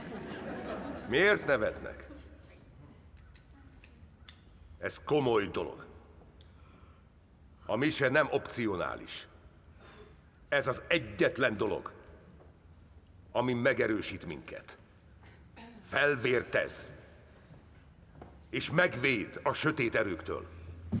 A Megtört szívekben lassan egy éve megszakítás nélkül hozza ugyanolyan minőségben a főszereplő karakterét, a Gothamben pedig a szokásosnál is keményebb és mélyebb színekben mutatja meg, hogy nincs olyan figura vagy szituáció, amit ne tudna rögtön a lehető legmagasabb szinten, hitelesen eljátszani.